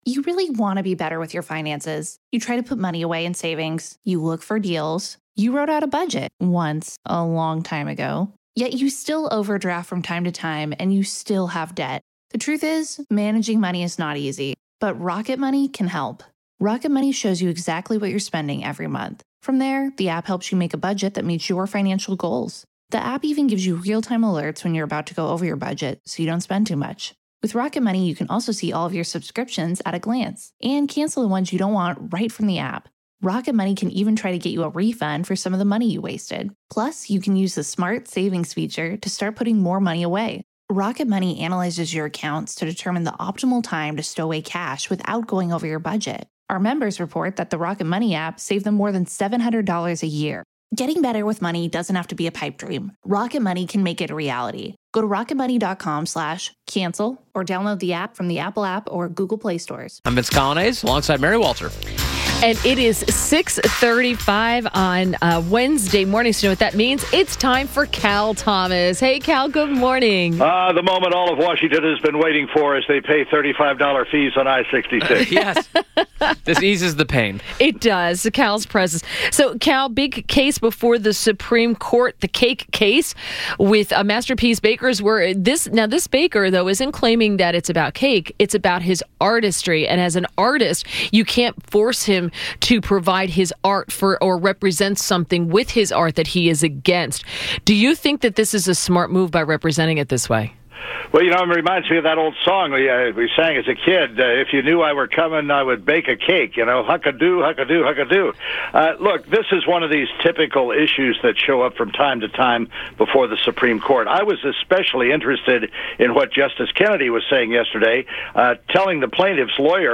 WMAL Interview - CAL THOMAS - 12.06.17